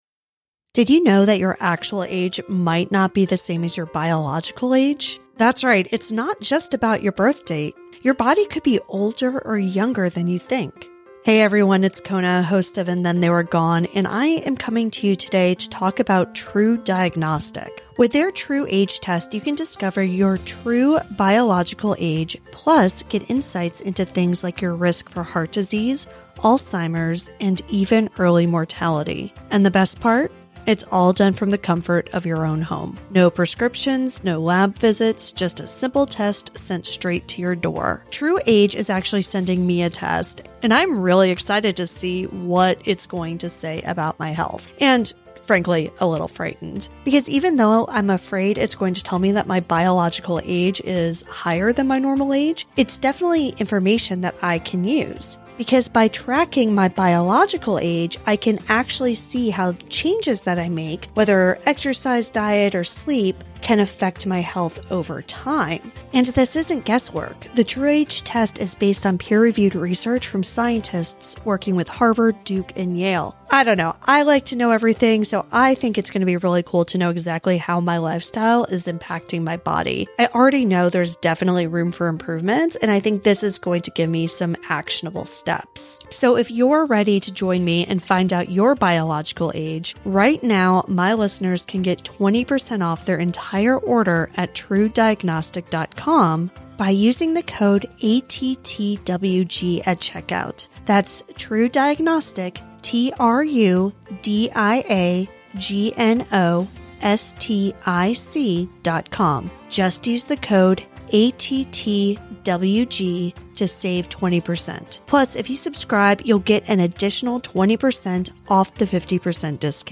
On Our Watch Season 2: New Folsom is a deeply-reported narrative podcast series from KQED. It follows the stories of two correctional officers in an elite unit who pay a high price for exposing corruption and abuse by their fellow officers.
interrogation tapes, 911 audio, prison phone calls, personal audio recordings, and hours of emotional interviews